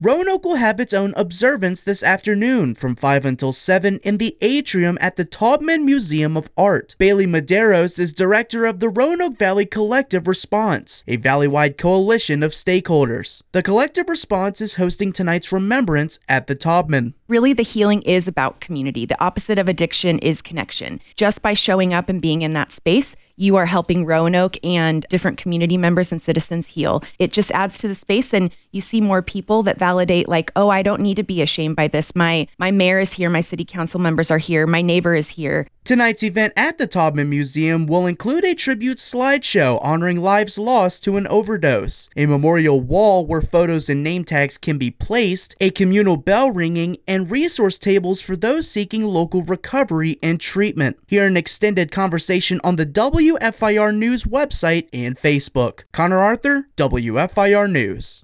Roanoke Valley Collective Response holds International Overdose Awareness Day event at Taubman today | News/Talk 960-AM & FM-107.3 WFIR